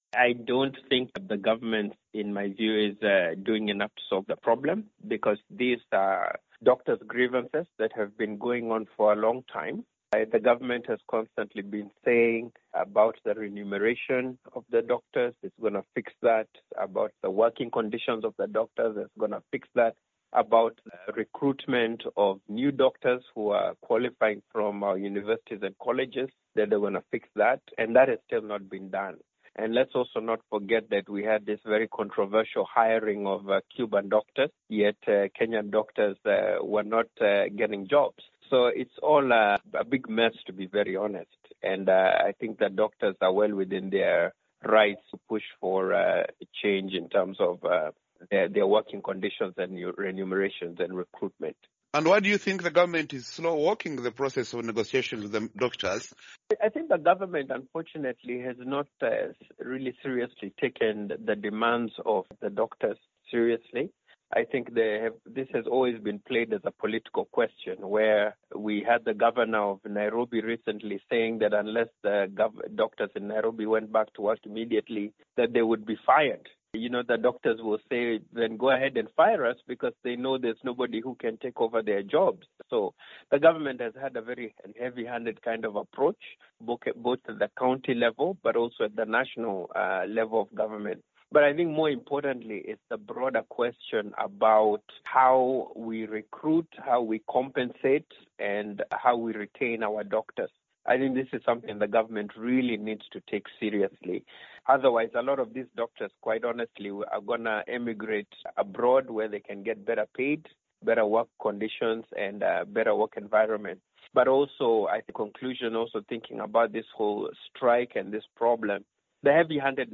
Thousands of doctors have stayed away from hospitals since last Thursday over poor pay and working conditions, despite a court order calling for talks between the doctors and the Health Ministry. Political analyst